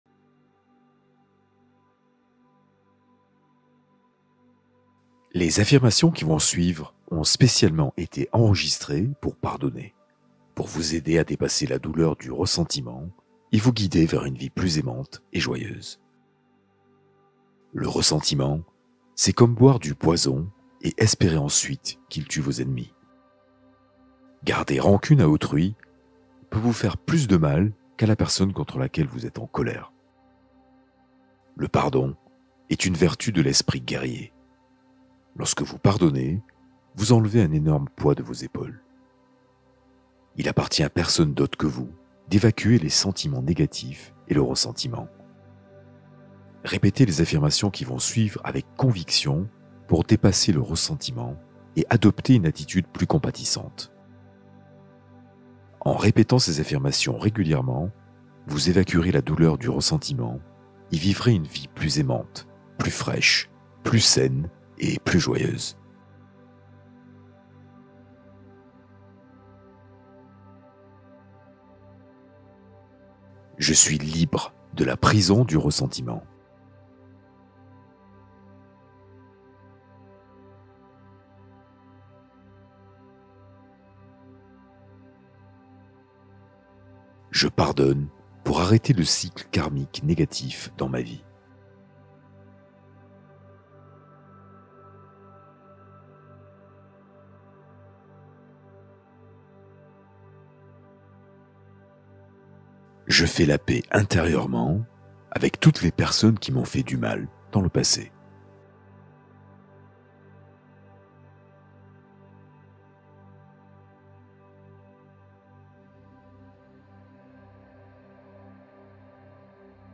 Activation de la glande pinéale : pratique guidée de focalisation